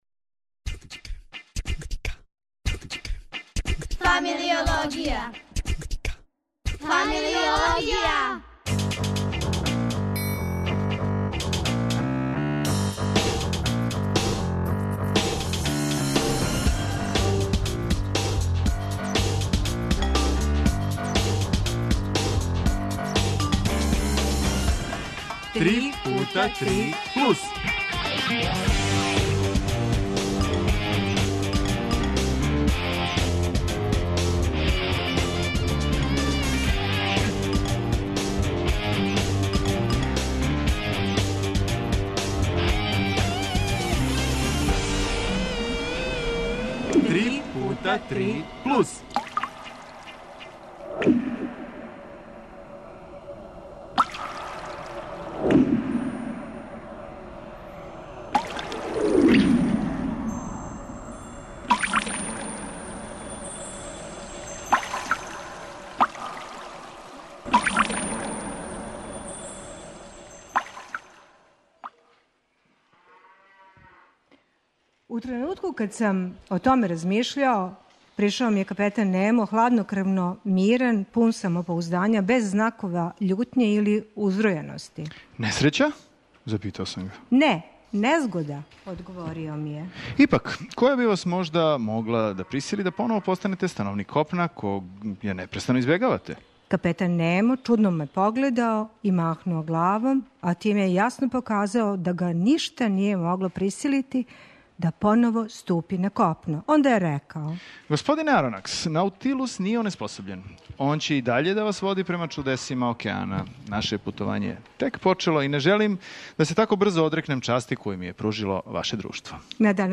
Емисија иде уживо из Музеја историје Србије - са изложбе о Пупину. Уз звуке свемира, телефона и брода који плови за Америку, ходаћемо са домаћинима и средњошколцима...